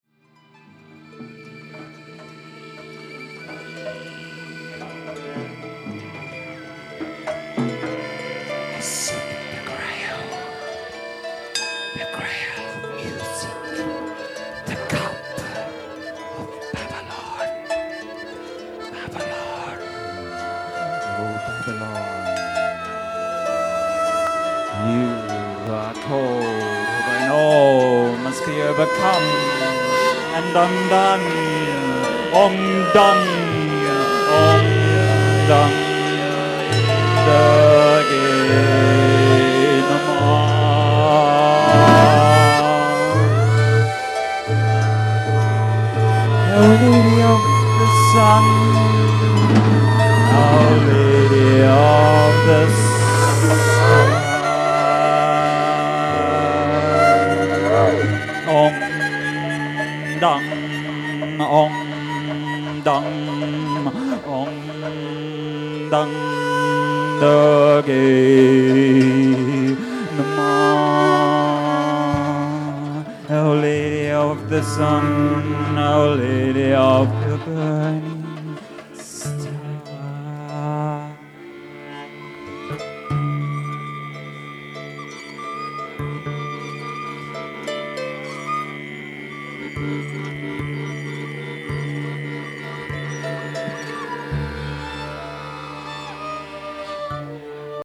invocational extract from improvisational performance
Celtic Harp, Panpipes, flute
Didgeridoo
Piano-Accordion & vocals
Bass Harmonic chanting, drumbreath
Singing bowl, drumbreath
Orchestral Percussion
Violin, Vocals, Conductor